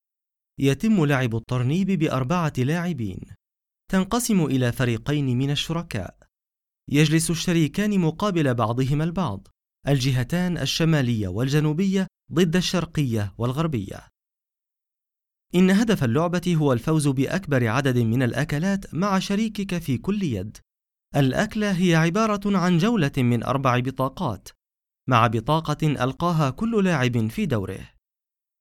Native standard Arabic voice, believable, narrative, and warm
Sprechprobe: eLearning (Muttersprache):